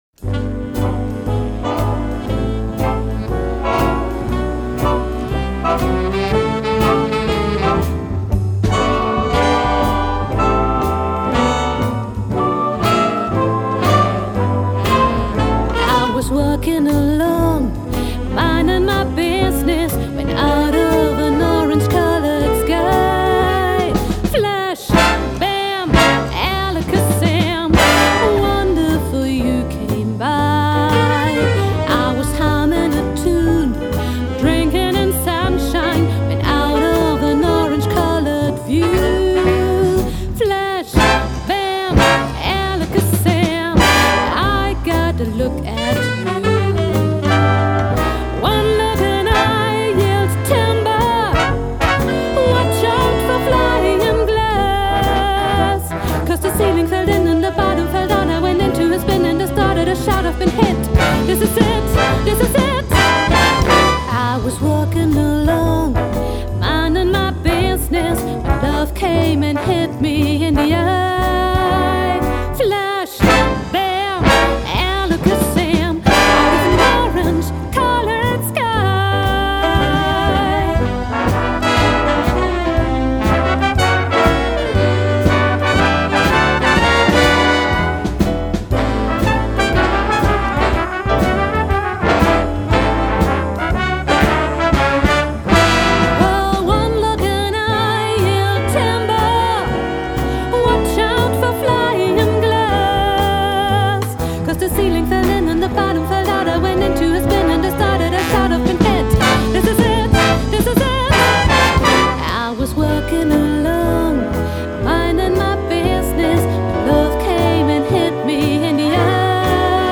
Lehle sunday driver für Kontrabass?
Signalfluss: Finger --> Evah Pirazzi medium --> alte Sperrholzomma --> Ehrlund --> Ehrlund Preamp --> L.R.Baggs Para Acoustic DI --> Glockenklang BlueSky --> DI Out --> etwas Kompression. Hobby BigBand 2019... alle spielen simultan, also nicht nacheinander eingespielt. War noch ein Mikrofon vor dem Kontrabass, wurde wegen Übersprechung aber nicht verwendet .